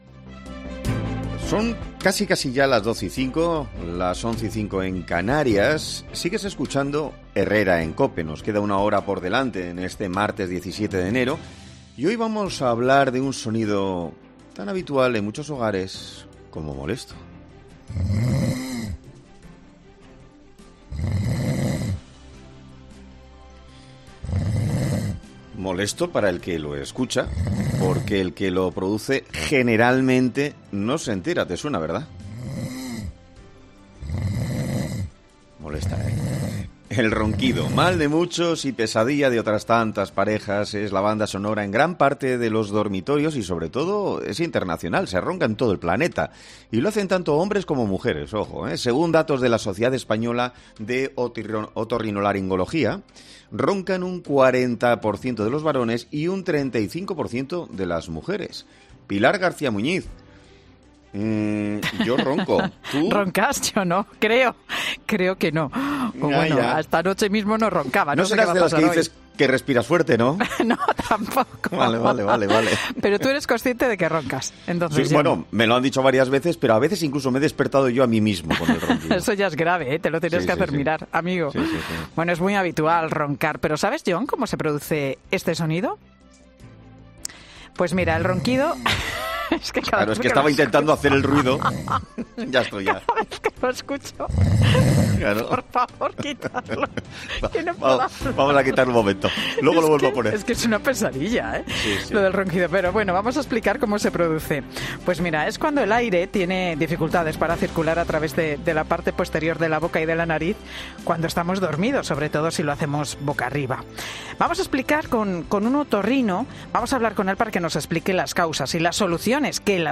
Un experto explica en 'Herrera en COPE' las multas a las que te enfrentas si tus ronquidos superan los decibelios permitidos